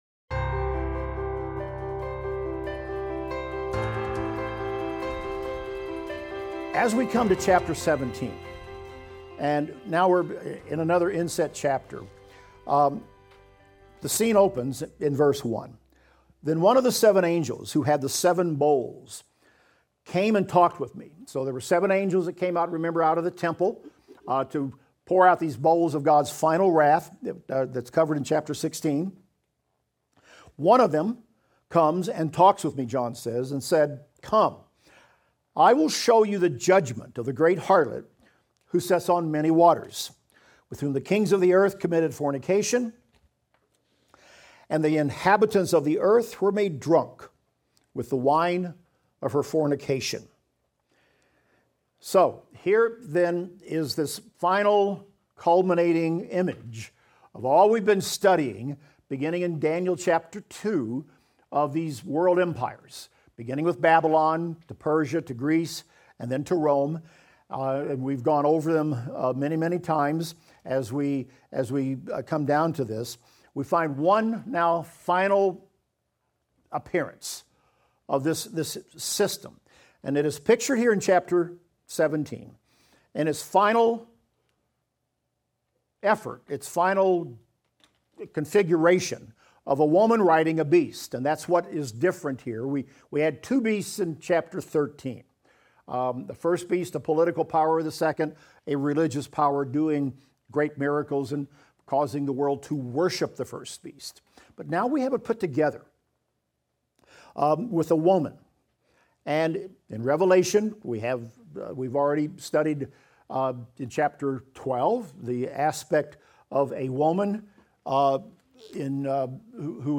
Revelation - Lecture 48 - audio.mp3